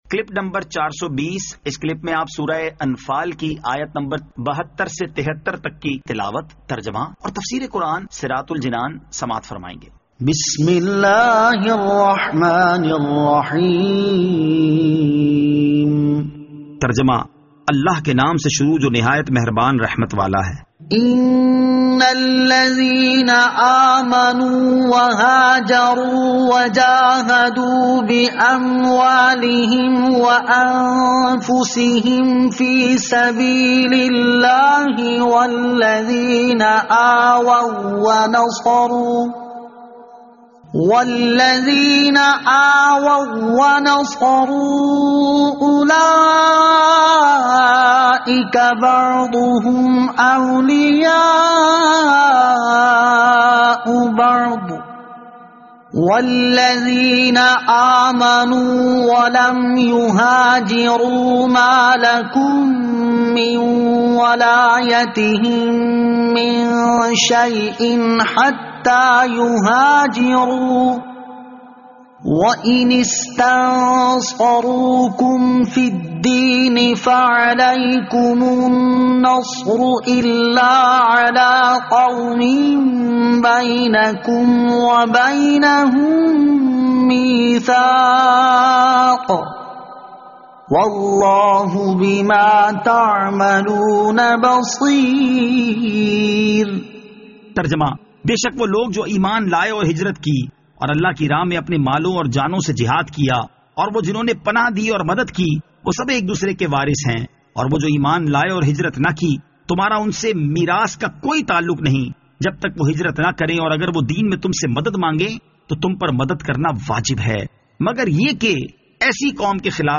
Surah Al-Anfal Ayat 72 To 73 Tilawat , Tarjama , Tafseer